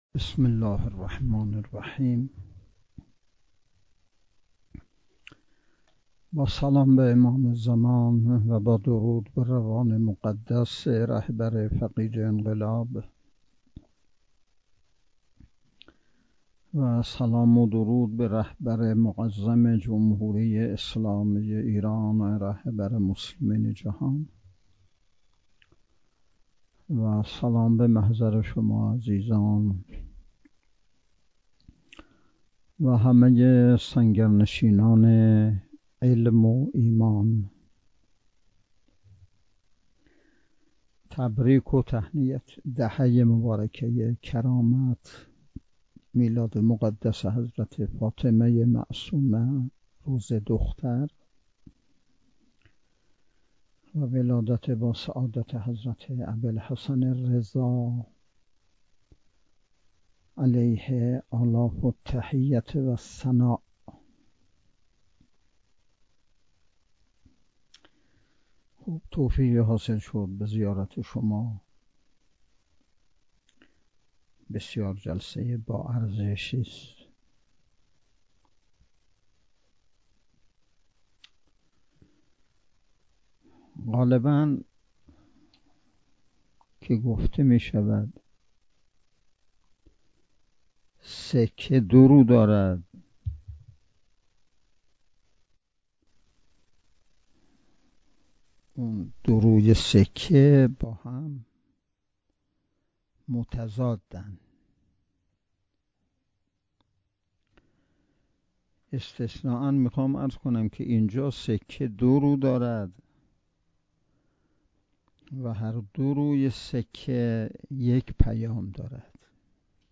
صوت کامل بیانات نماینده ولی فقیه در خراسان جنوبی در نشست بصیرتی روسای عقیدتی سیاسی نیروهای مسلح استان جهت استفاده عموم علاقه مندان منتشر شد.